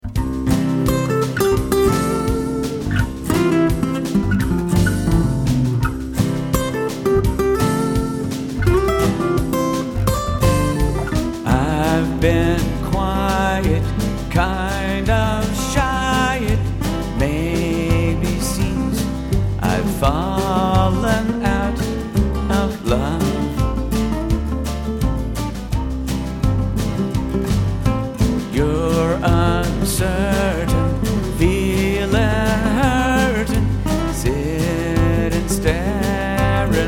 Here's a pleasant country ditty
It's a little country love song I wrote in a sincere Ernest Tubb tradition.
The hot lead acoustic guitar work
a "resonator bass". This is as close as I've heard to a string bass
The drums are again a coupl'a Alesis SR-16s I programmed.